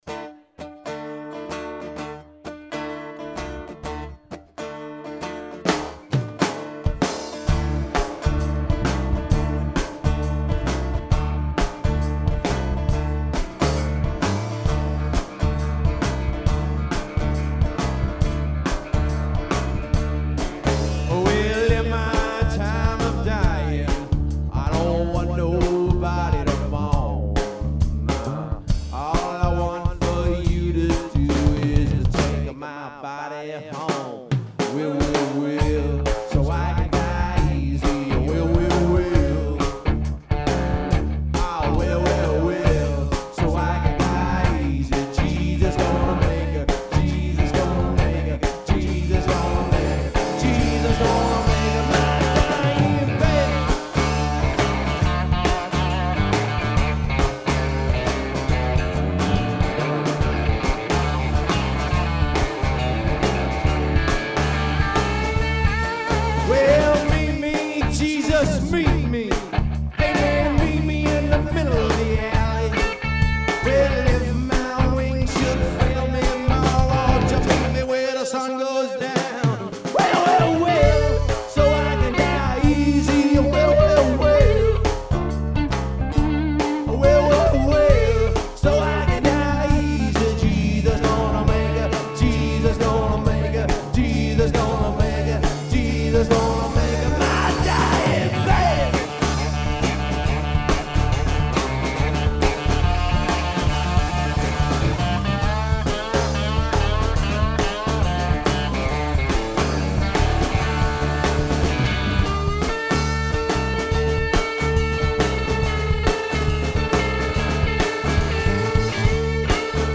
Live at Southpaw Brooklyn NY November 6'2009
Guitar, Vocals
Lead Guitar
Bass Guitar
Drums